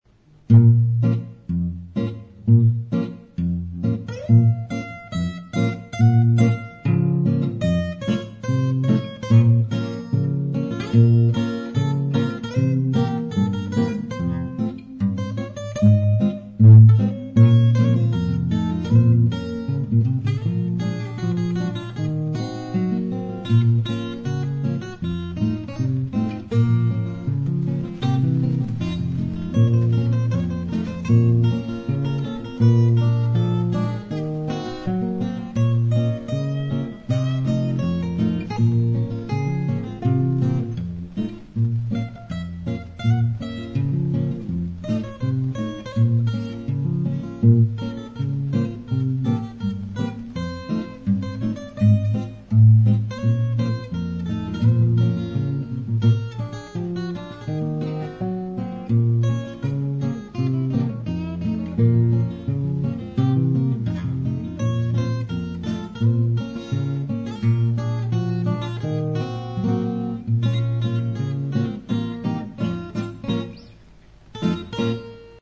А вот это настоящая гитара, а не синтез (527 кБ):